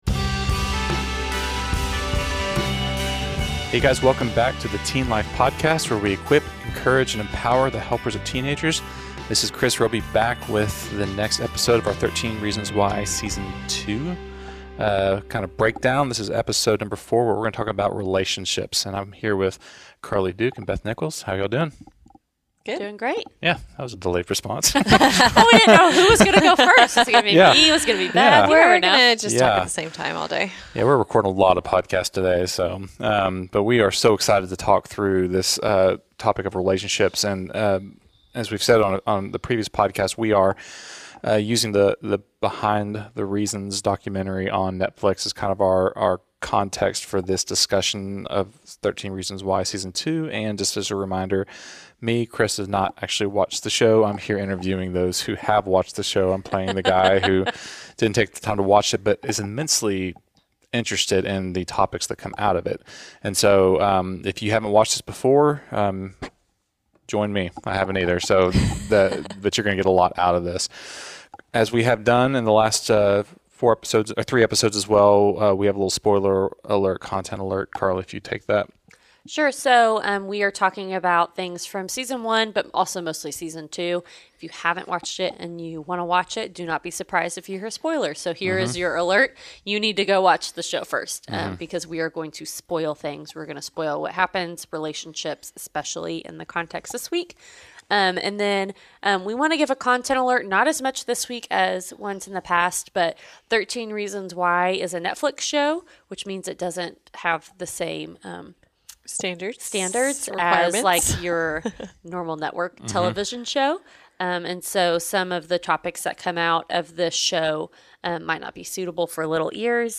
In this episode of the Teen Life Podcast’s series on the Netflix show 13 Reasons Why, the Teen Life staff is talking about teen relationships.